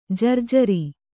♪ jarjari